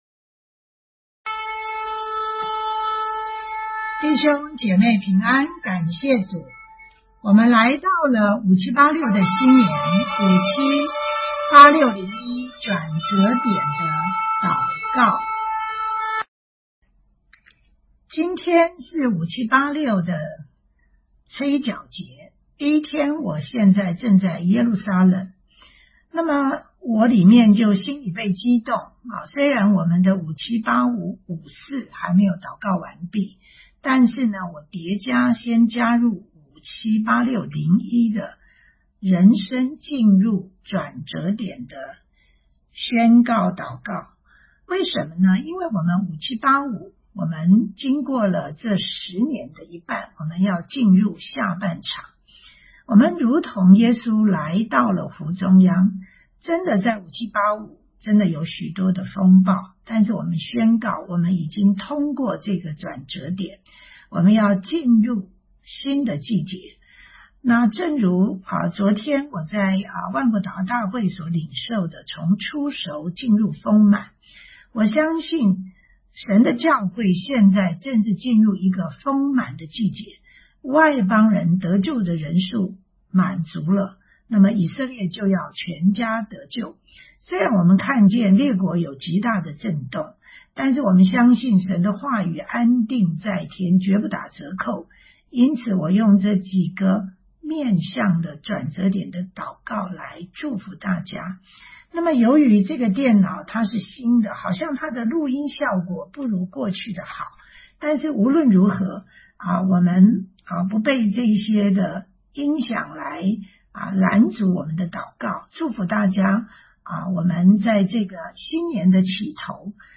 转折点祷告